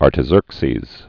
(ärtə-zûrksēz) Died 424 BC.